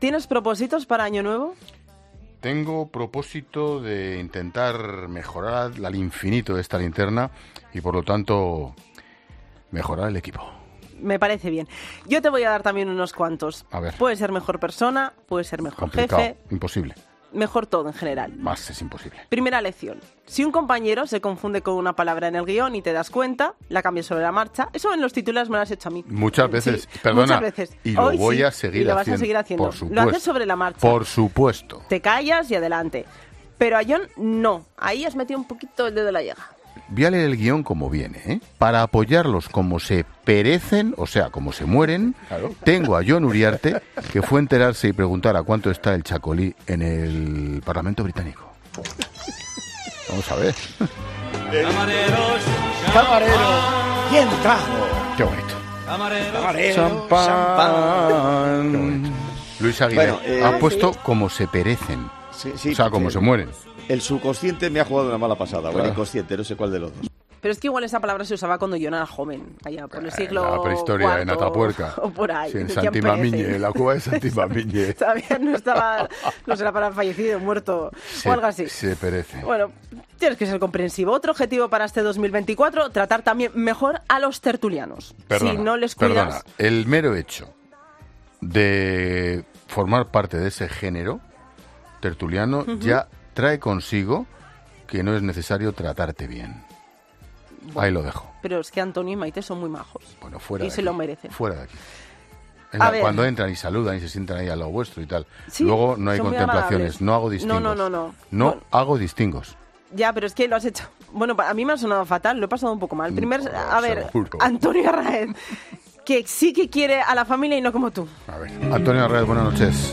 A pesar de que sabía a lo que se refería, el error modificaba completamente el sentido de la frase y Expósito no pudo pasarlo por alto: "Voy a leerlo como viene", advirtió antes de leerlo en directo.